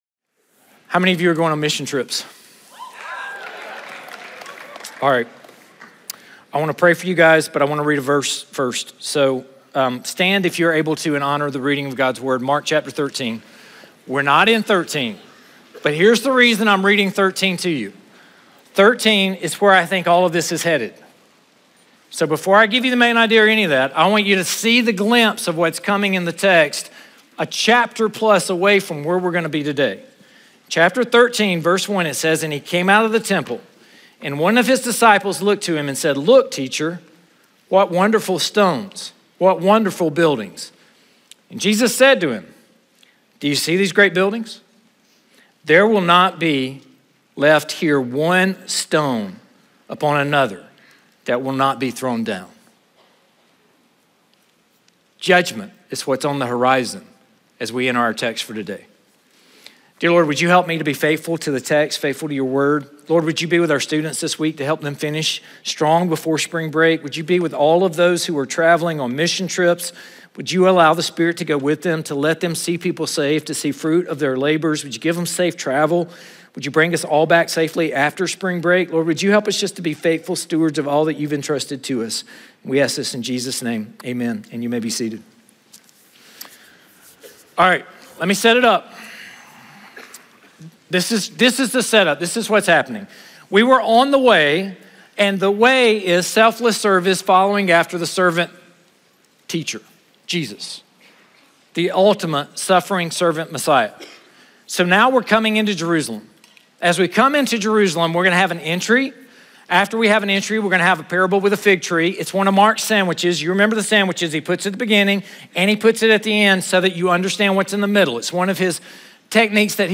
Chapel Messages